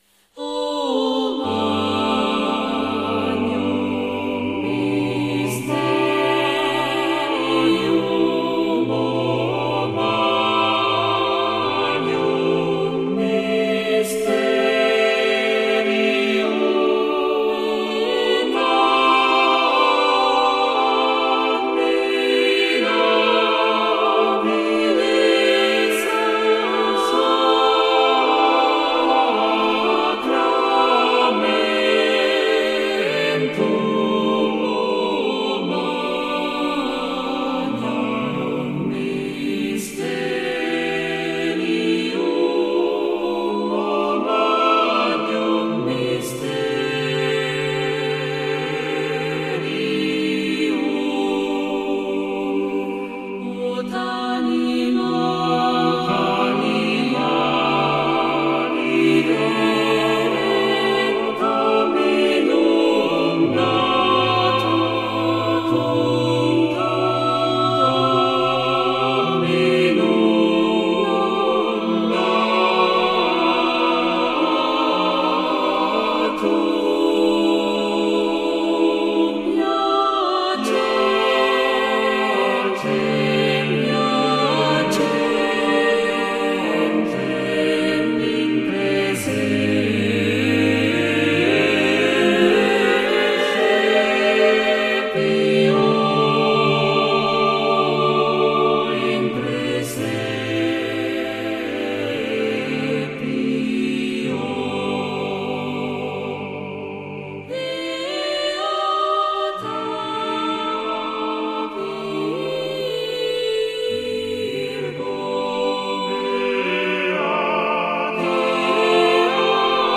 Obra Coral de música sacra para coro mixto SATB con divisi.
Sacred Choral song per mixted choir SATB with divisi.
Formato: SATB (divisi)